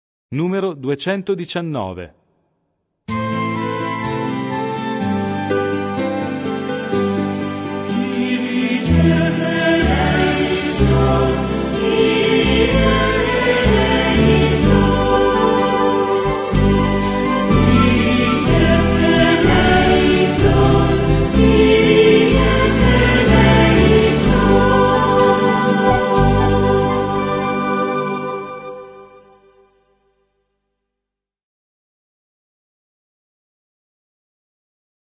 Il canto di avvento prepara, come in un cammino, l'avvicinarsi al Natale: ecco allora che i tempi musicali sono quasi tutti in 3/4 o 6/8, come a mimare quel passo che ci accompagna verso il mistero: canti di meditazione e di incontro con la Parola che salva: non ci sarà più il Gloria, ma daremo spazio al canto della misericordia di Dio, invocando Kyrie, eleison!